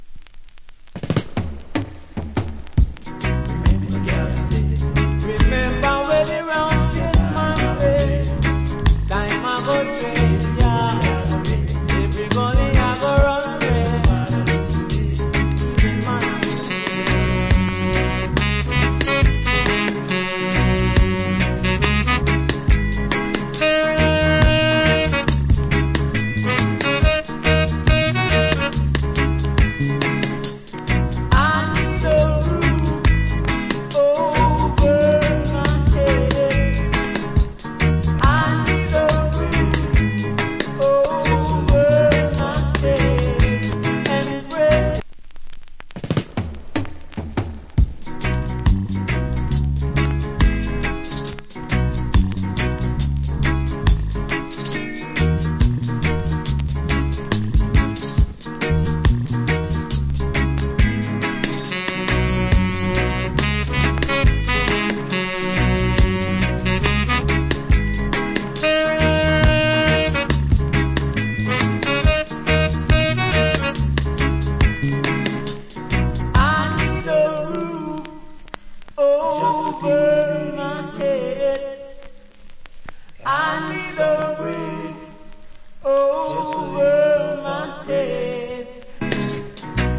Great Reggae Vocal.